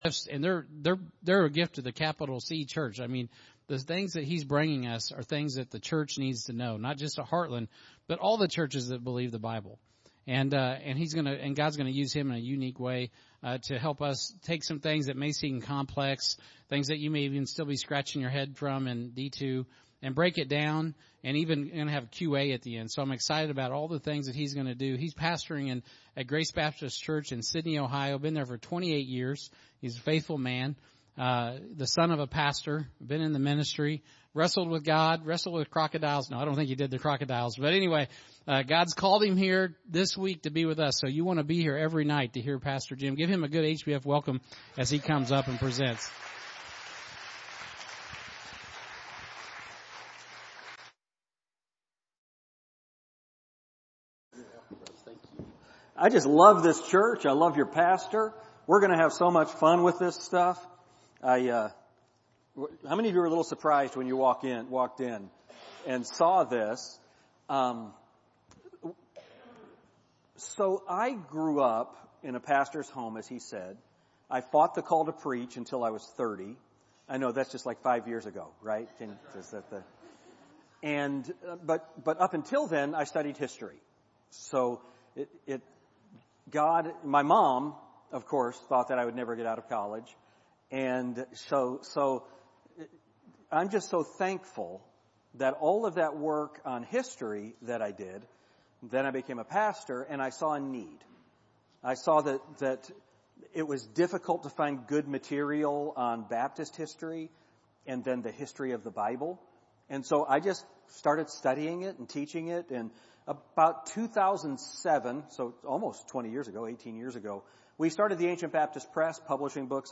Sermons | Heartland Baptist Fellowship
The Bible Conference